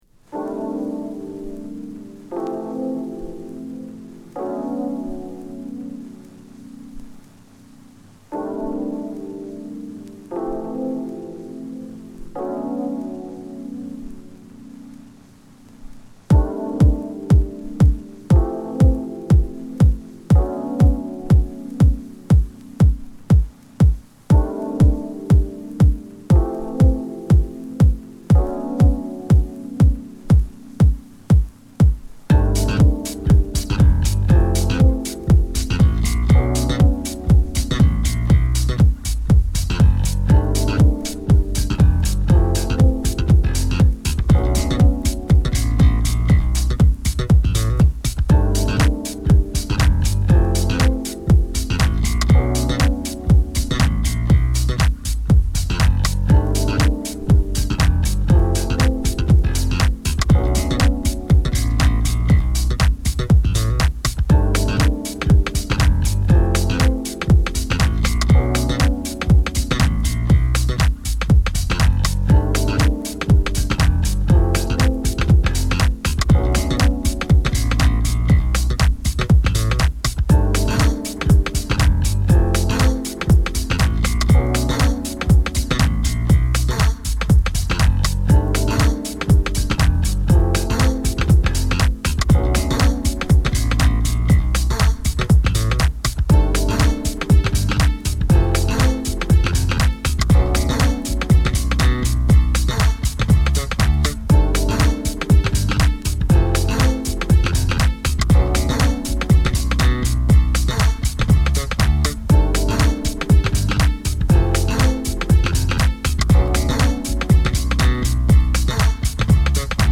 Broken Beats , Dance Track , Deep House , Electronic
Jazz Funk